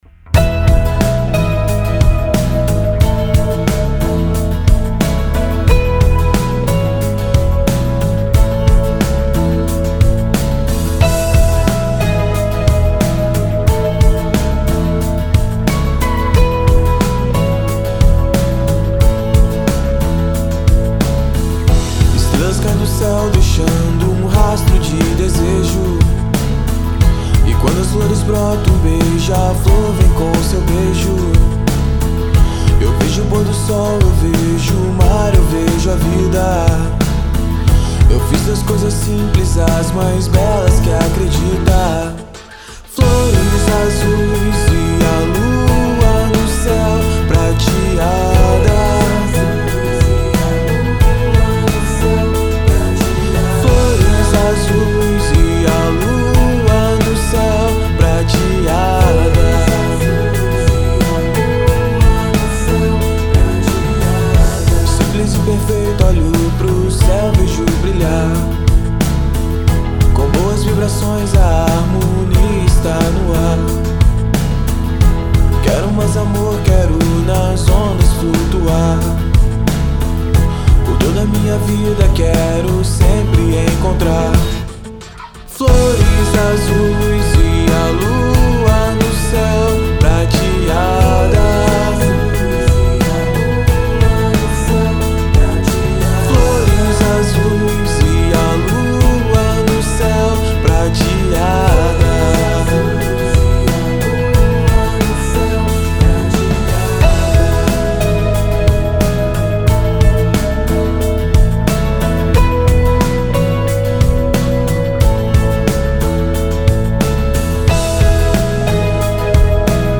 EstiloSurf Music